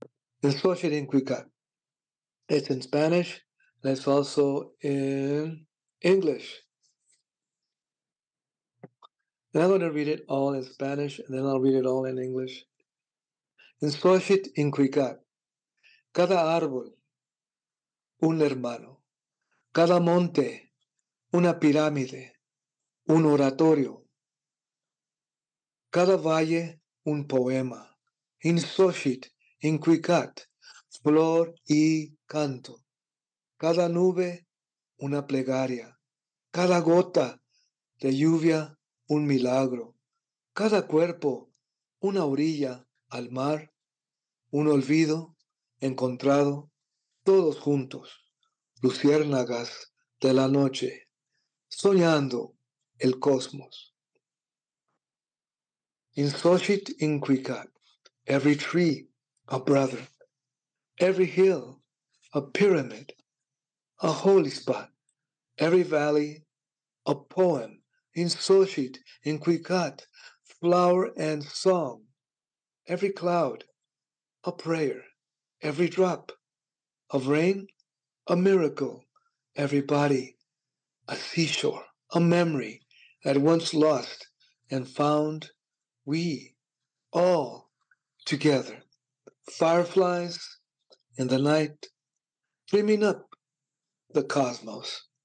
Juan Felipe Herrera, twenty-first Poet Laureate of the United States and a 2024 MacArthur “genius grant” recipient, sat down with Library of America to read and discuss poems from Latino Poetry: The Library of America Anthology.